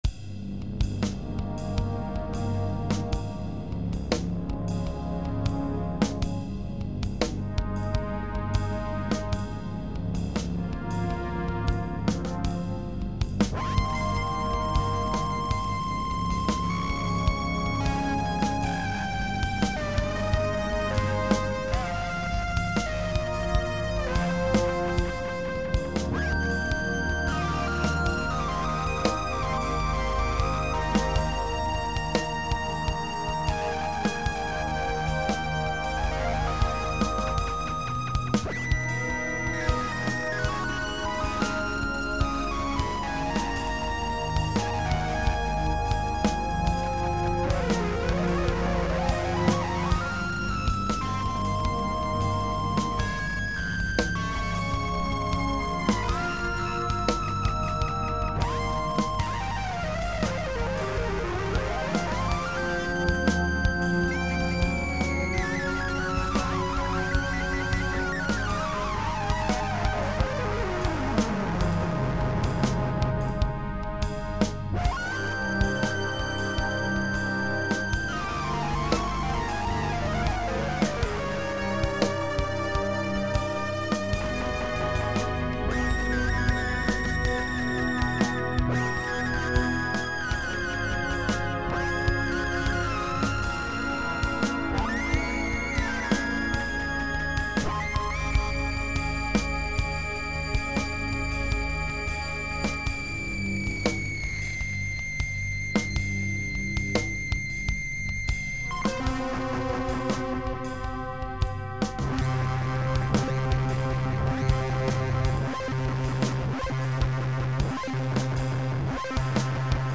At this point I'd moved past the 4-track recorder and was recording everything digitally onto the PC.
You can see the quality of the recording efforts was pretty awful.